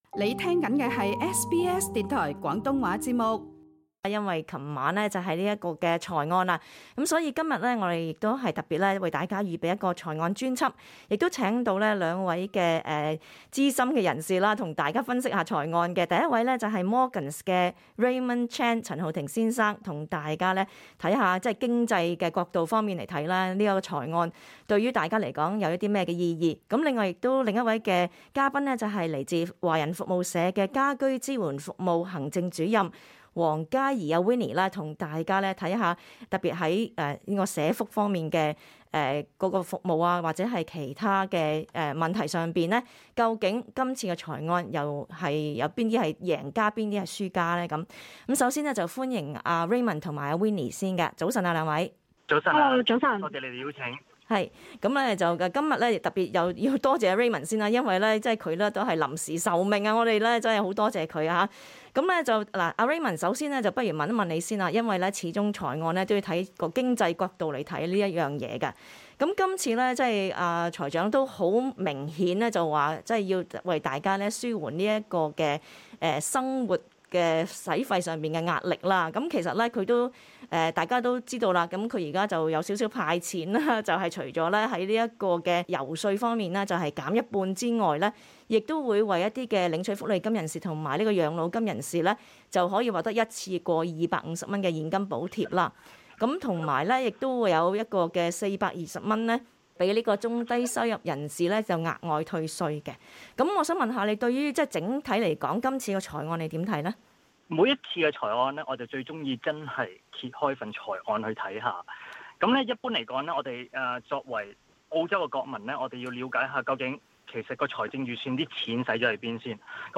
cantonese-_budget_panel_upload_fina2.mp3